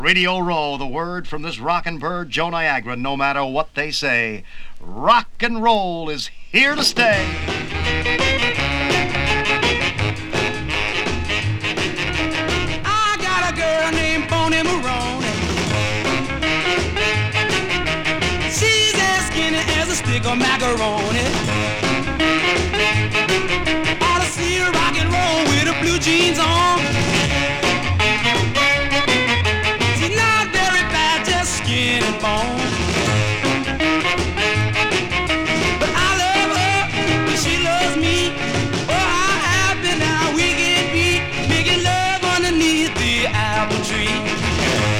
※Mono音源をStereoにしています。
Rock, Pop, Rock & Roll　USA　12inchレコード　33rpm　Stereo